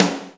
SNARE 012.wav